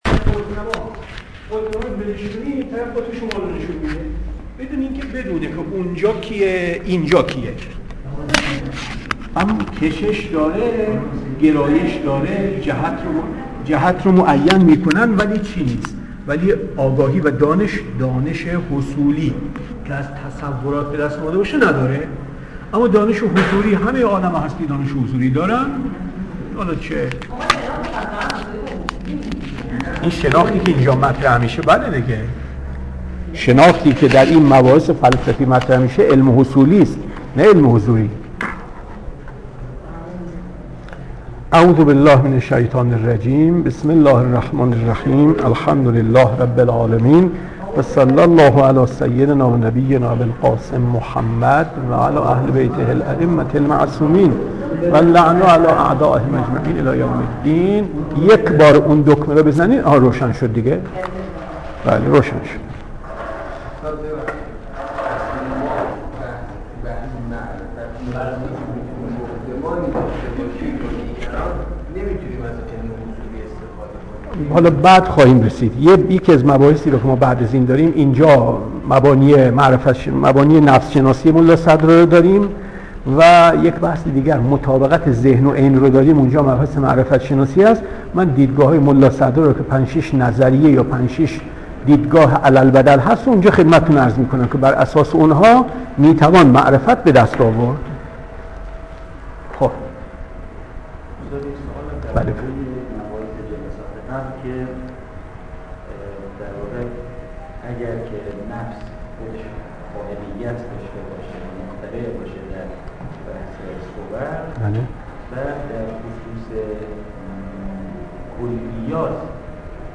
درس صوتی